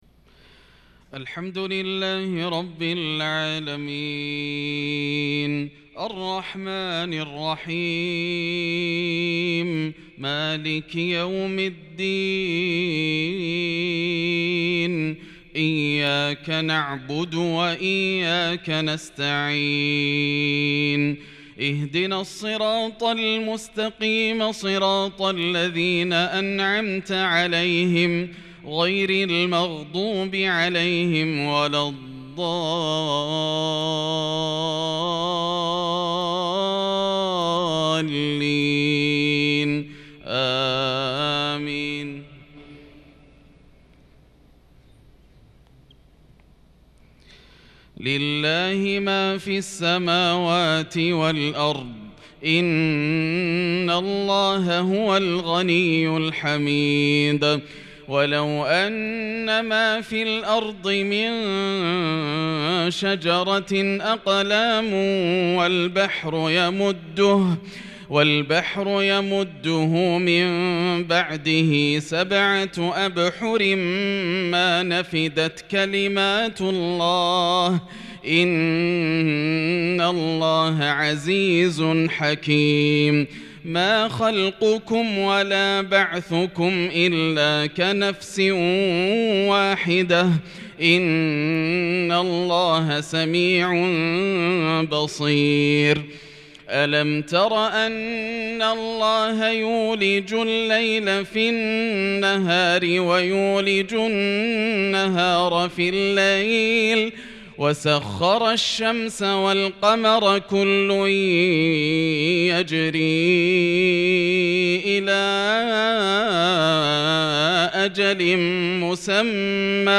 صلاة العشاء للقارئ ياسر الدوسري 7 محرم 1443 هـ